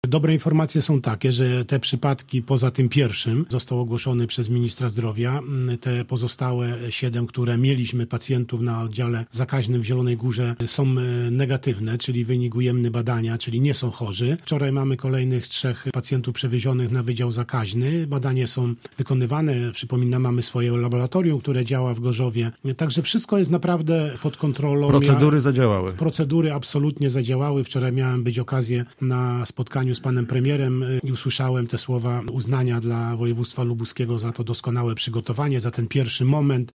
Poranny gość Radia Gorzów uspokaja Lubuszan i potwierdza, że do teraz mamy tylko jeden przypadek zarażenia.